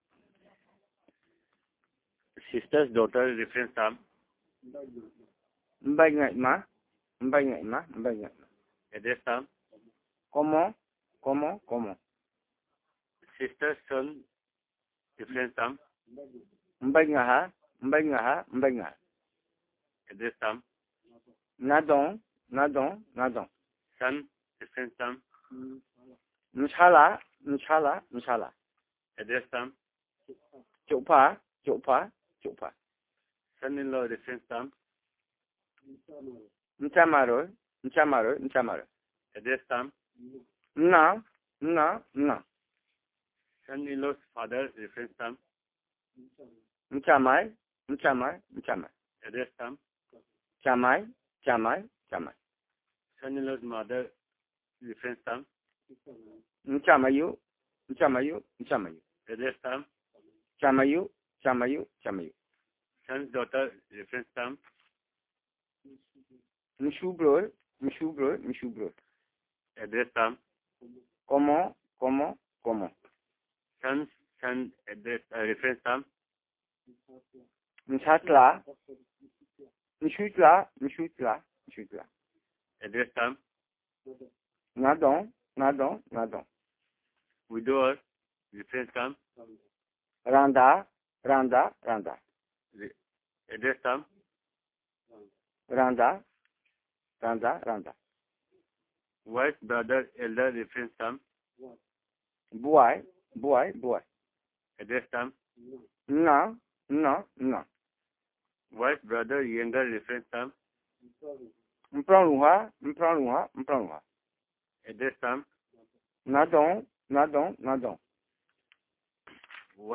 Elicitation of words about kinship terms and endearments.